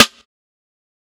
Metro Dry Perc.wav